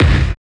CYBER KIK 1.wav